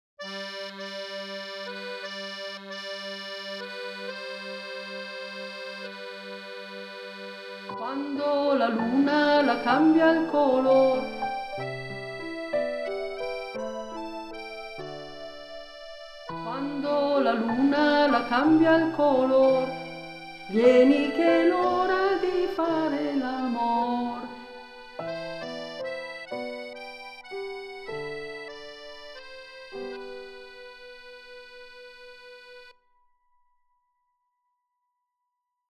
una canzone tradizionale resa celebre da Gigliola Cinquetti